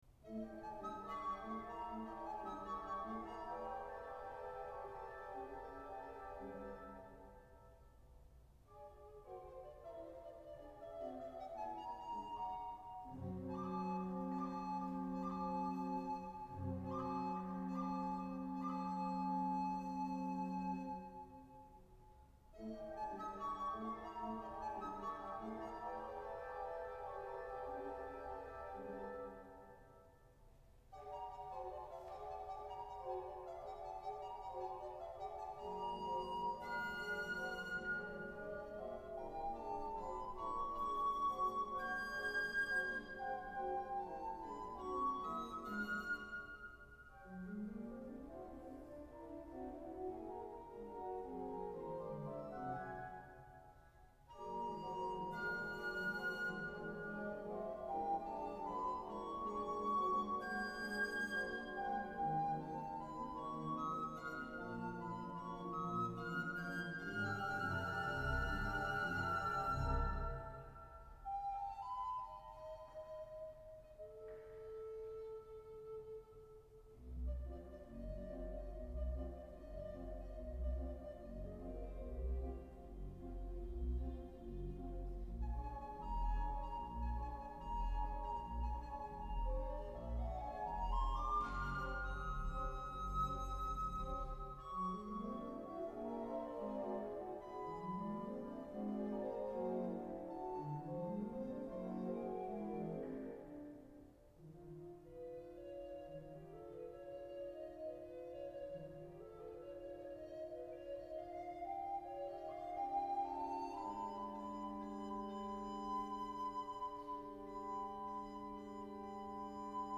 a piece for flute/alto recorder & organ